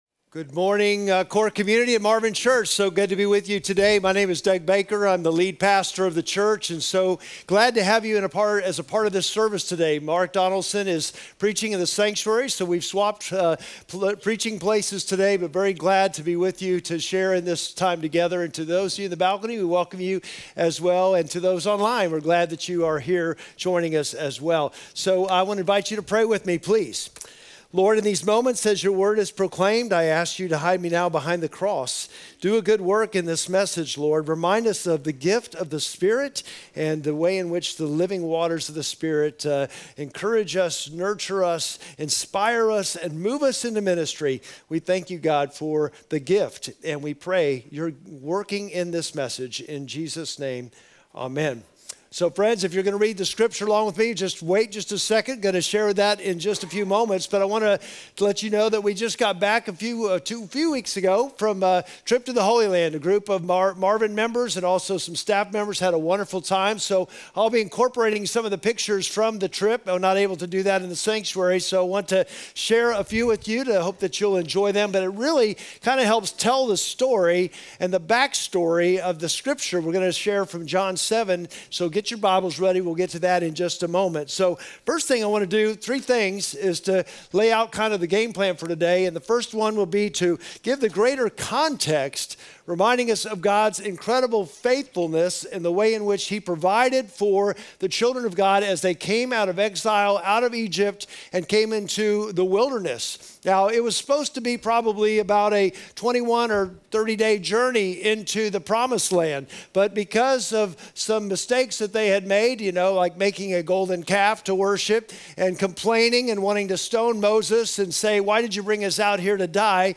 Sermon text: John 7:37-39